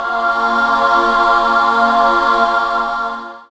SYN DANCE0HL.wav